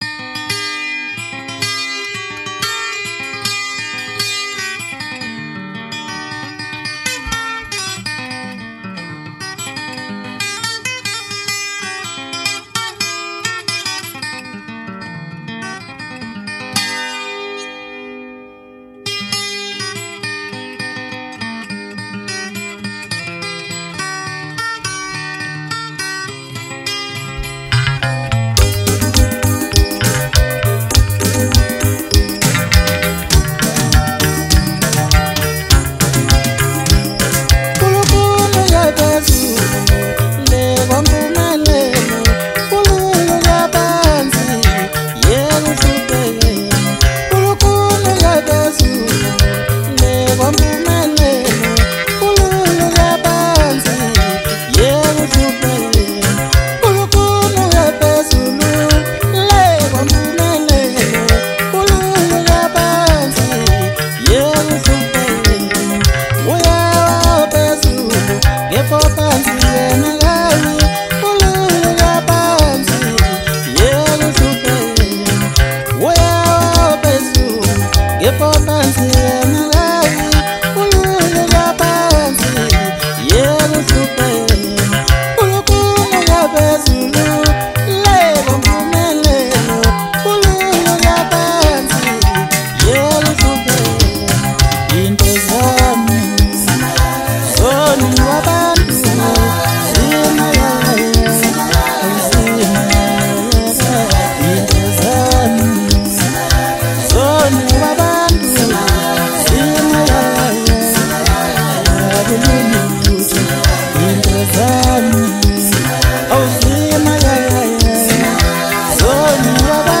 MASKANDI MUSIC